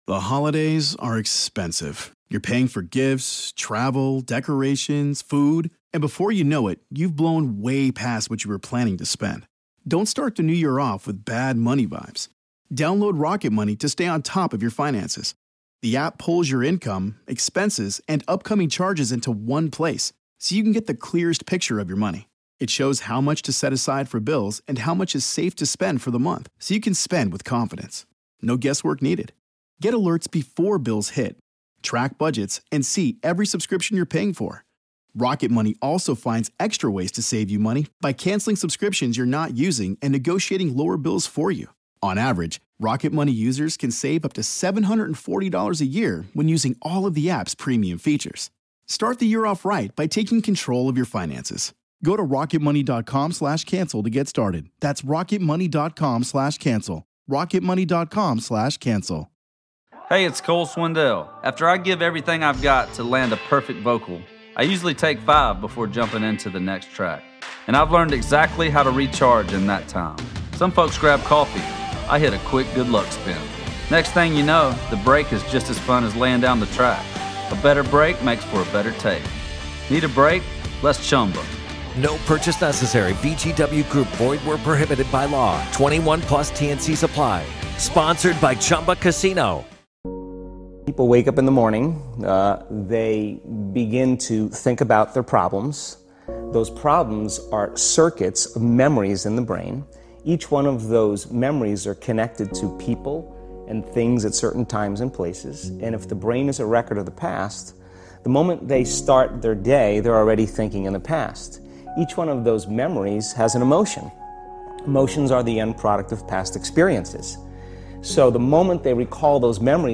Speaker: Joe Dispenza